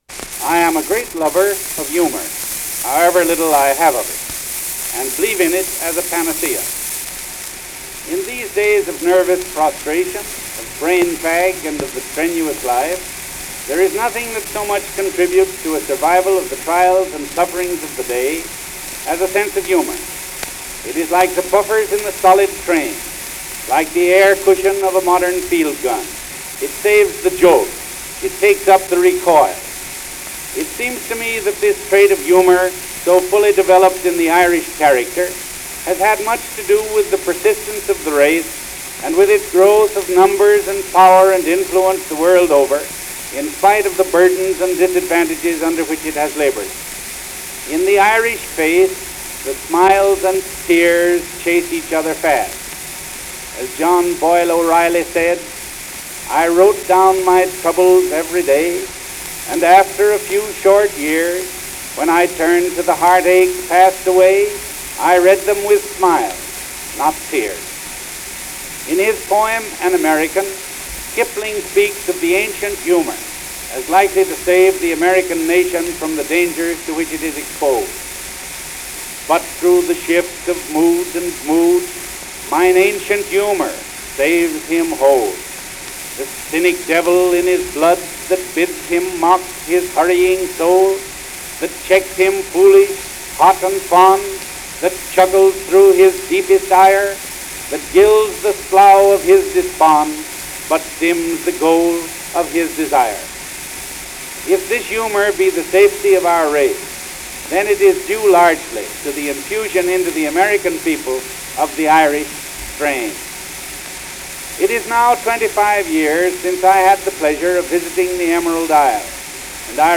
President Taft discusses a visit to Ireland and Irish poetry and humor.